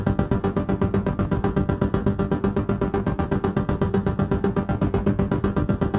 Index of /musicradar/dystopian-drone-samples/Tempo Loops/120bpm
DD_TempoDroneC_120-G.wav